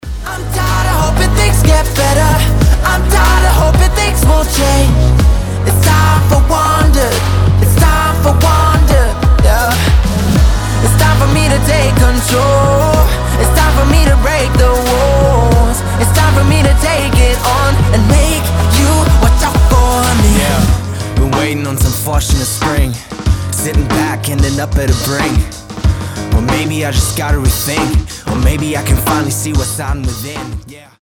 • Качество: 320, Stereo
красивый мужской голос
приятные
RnB
вдохновляющие